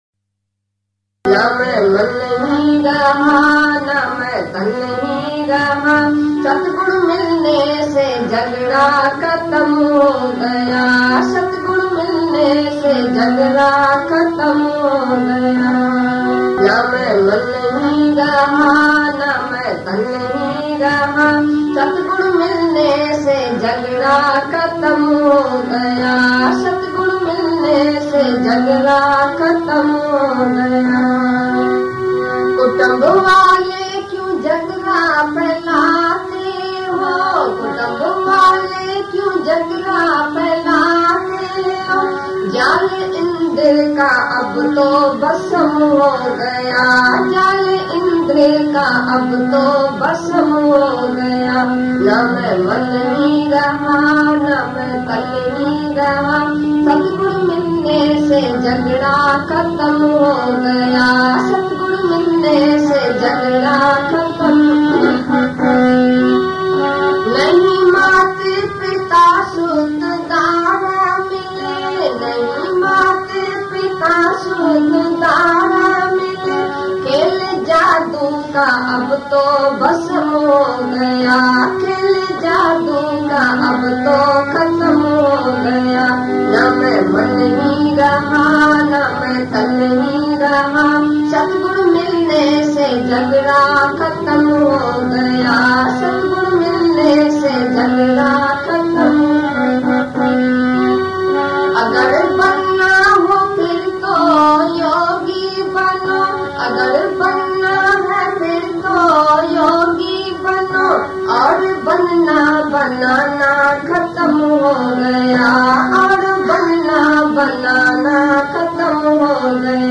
Naa Mein Mann Hi Raha Naa Mein Tann Hi Bhajan | ना मैं मन ही रहा ना मैं तन ही रहा सतगुरु मिलने भजन - DGSM Bhajan Lyrics